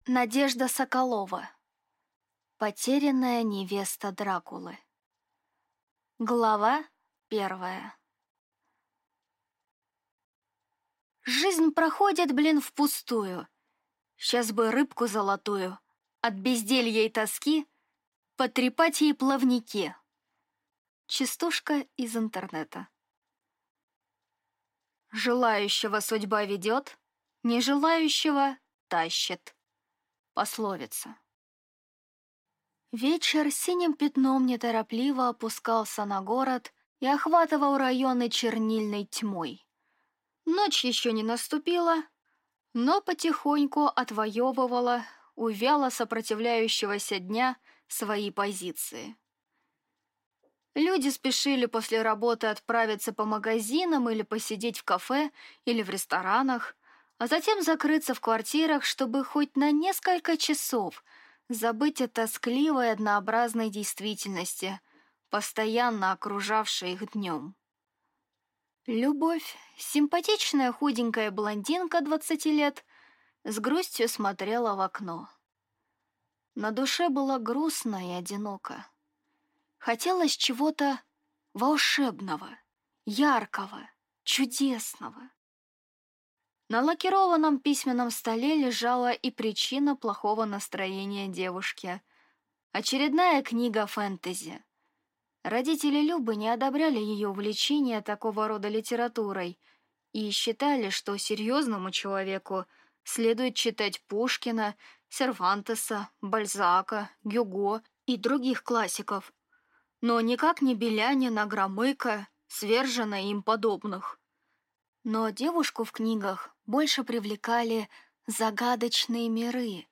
Аудиокнига Потерянная невеста Дракулы | Библиотека аудиокниг